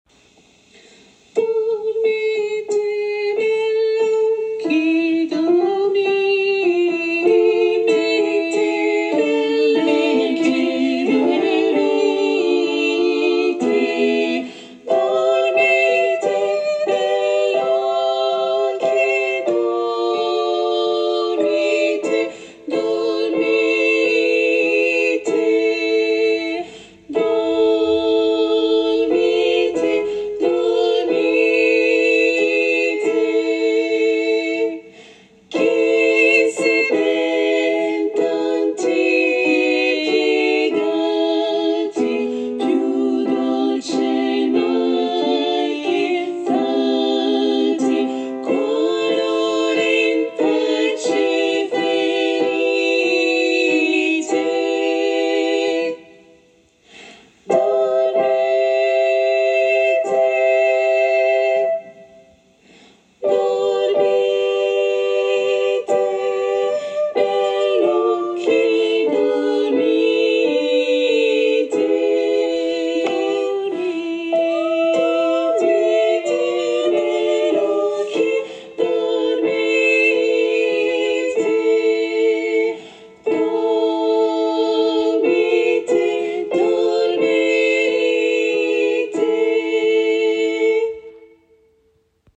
- Œuvre pour 3 voix (SSA ou TTB) + basse continue ; compositeur : Luigi Rossi
Tutti a capella